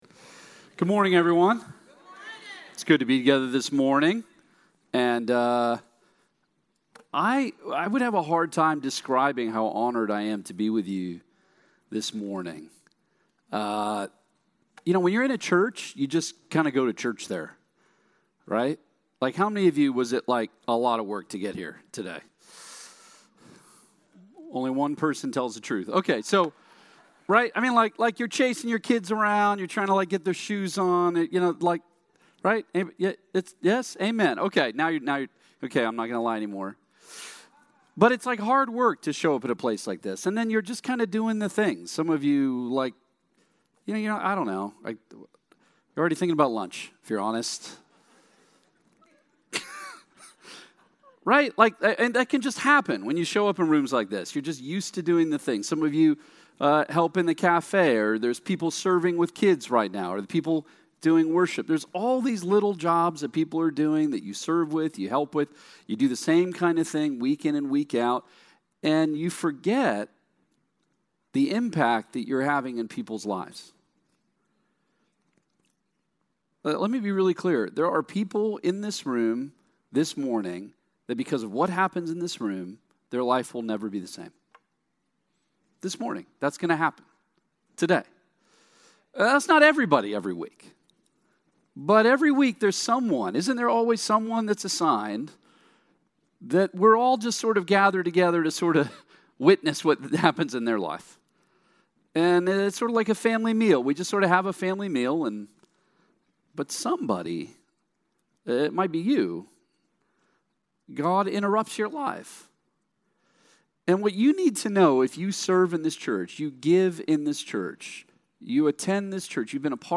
brings today's message.